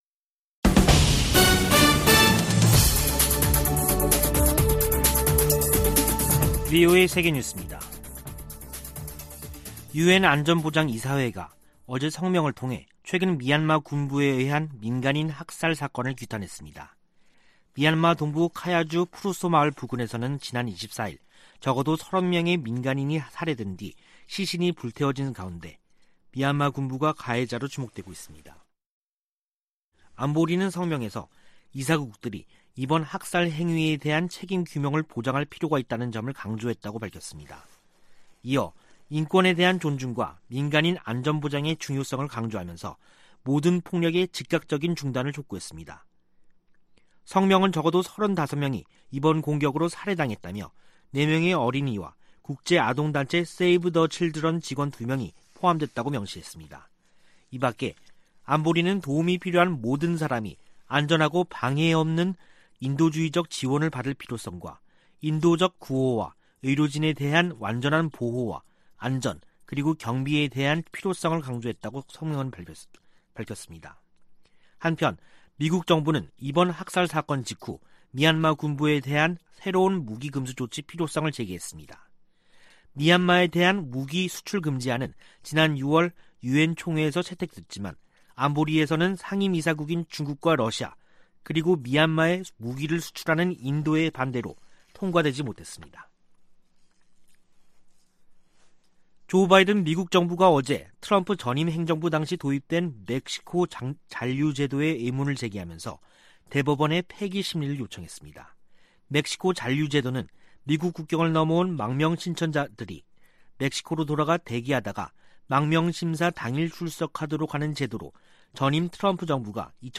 VOA 한국어 간판 뉴스 프로그램 '뉴스 투데이', 2021년 12월 30일 3부 방송입니다. 미 국방부는 다양한 안보 문제에 대해 한국 동맹군과 정기적으로 합동 계획을 수립하고 있다고 밝혔습니다. 올해 김정은 북한 국무위원장은 이례적으로 군사훈련 관련 공개 활동을 하지 않은 것으로 나타났습니다. 북한이 지난해 1월부터 코로나바이러스 유입을 막겠다며 국경을 전면 봉쇄한 가운데, 아직 재개방 조짐은 보이지 않고 있습니다.